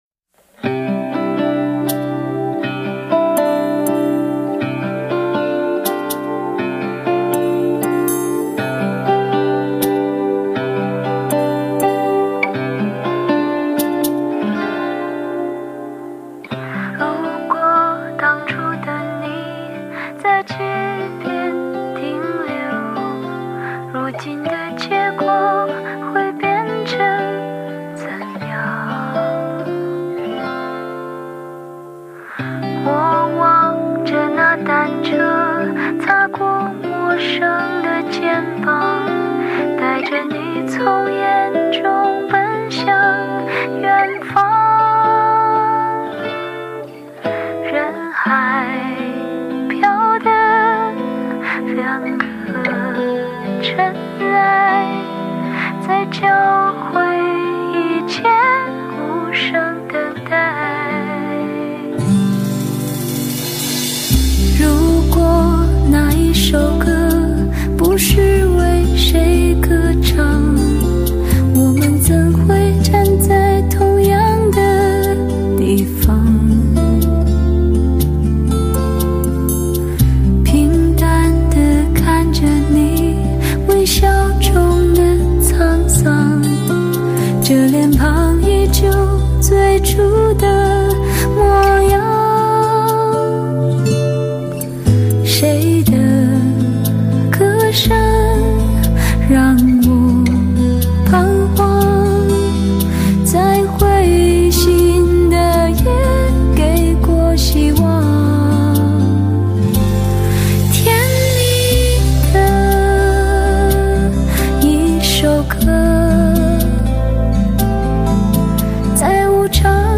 一串音符洒落在你的心间，清澈的，没有一丝尘埃；